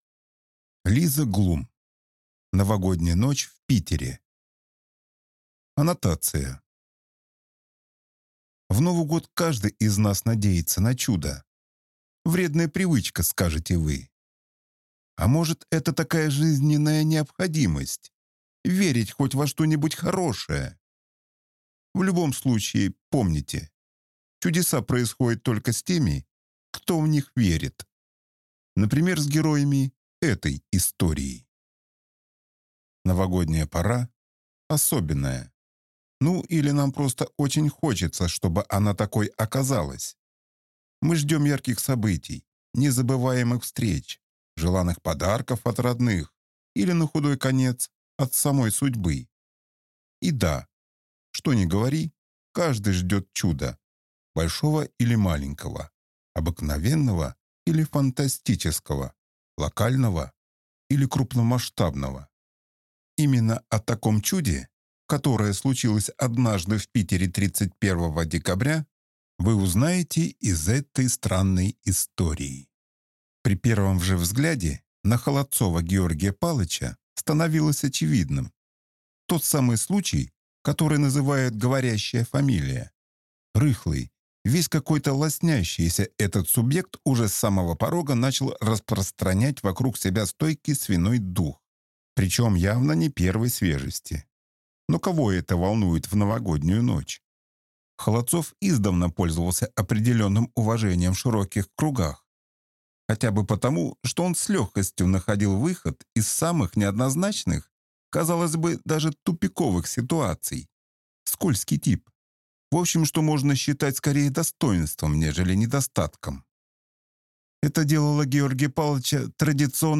Аудиокнига Новогодняя ночь в Питере | Библиотека аудиокниг